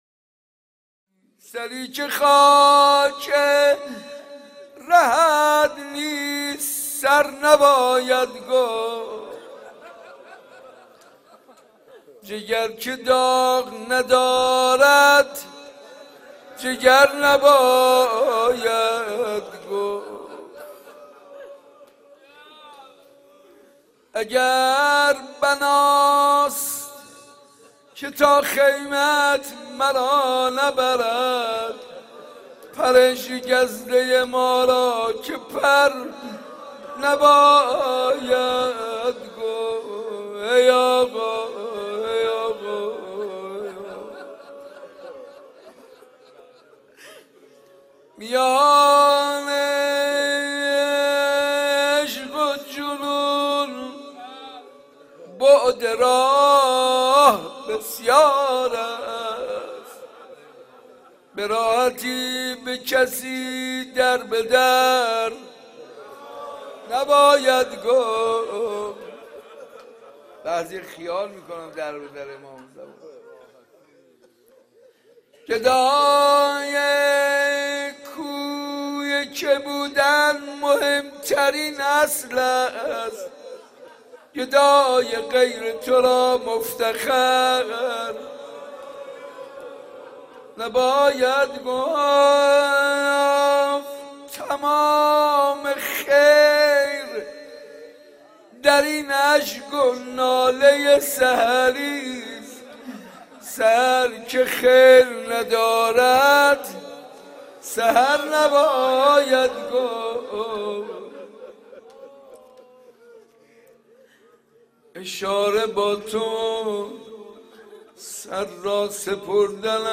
حسینیه ی صنف لباس فروش ها
روضه
مداحی
نوحه